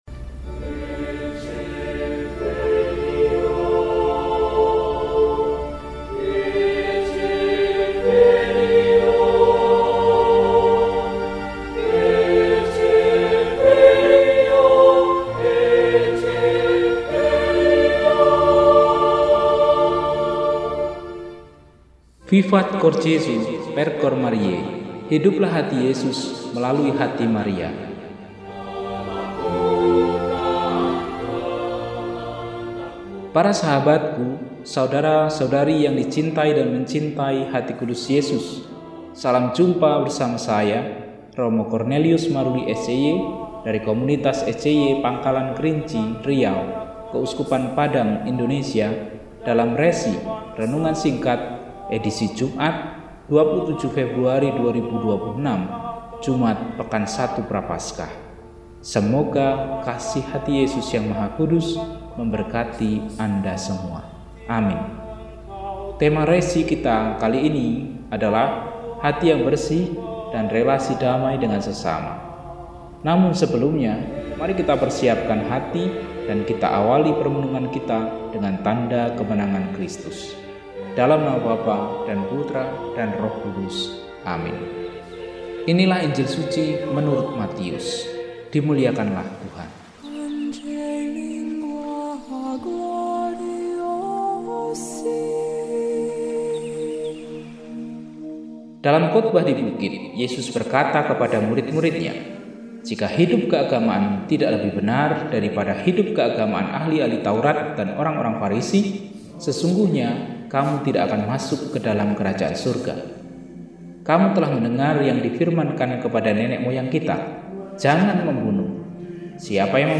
Jumat, 27 Februari 2026 – Hari Biasa Pekan I Prapaskah – RESI (Renungan Singkat) DEHONIAN